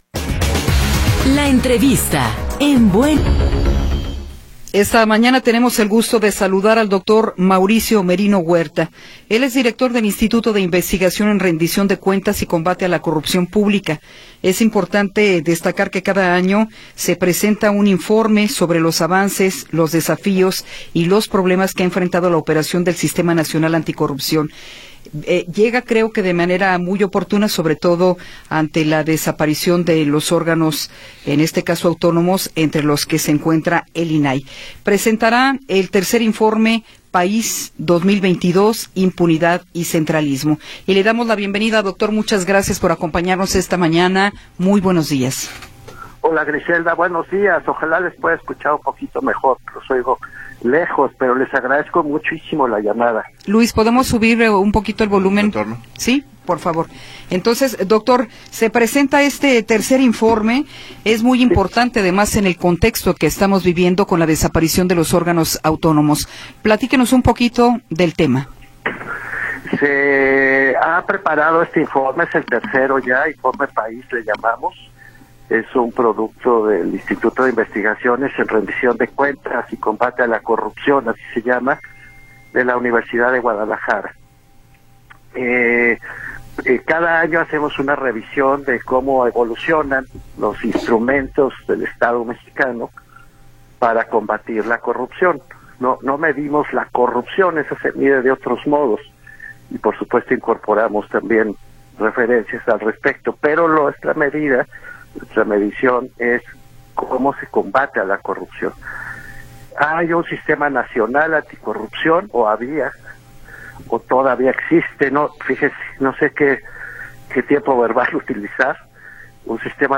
entrevista-6.m4a